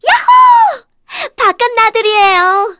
Dosarta-vox-select_kr.wav